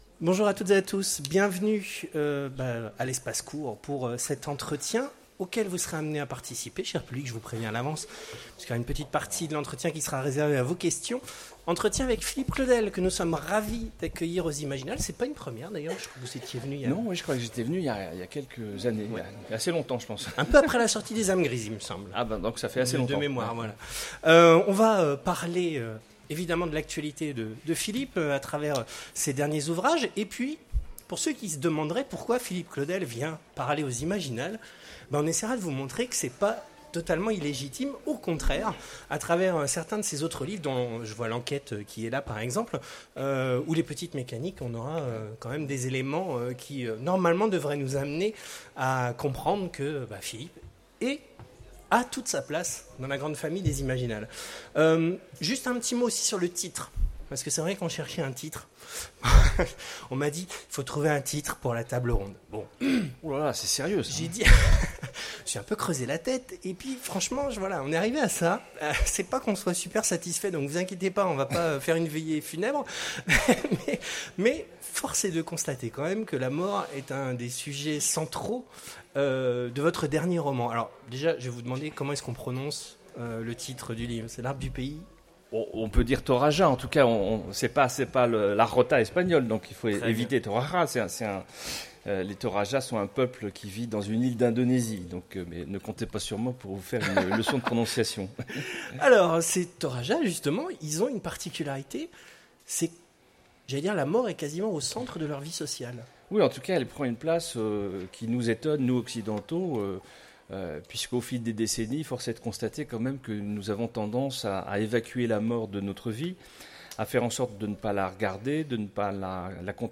Imaginales 2016 : Conférence Survivre…